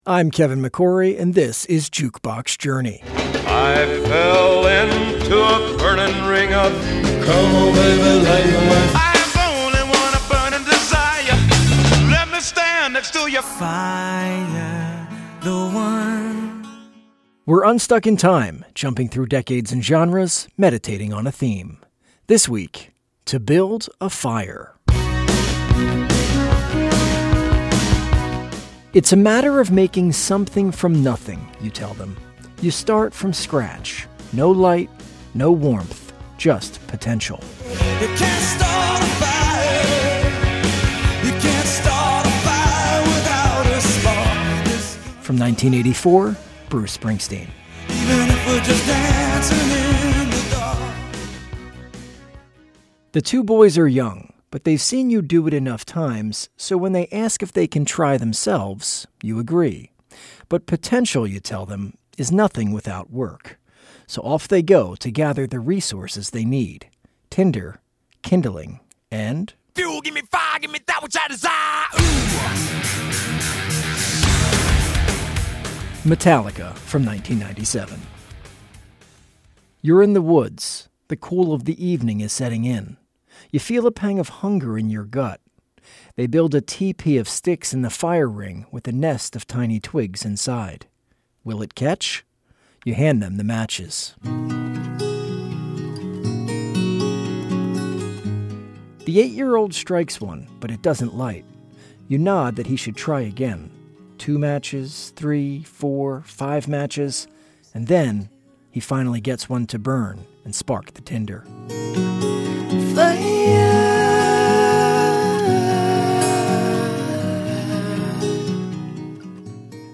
This edition of Jukebox Journey riffs on the concept of fire, with music ranging from turn-of-the-century boy bands to historical field recordings from the 1920s.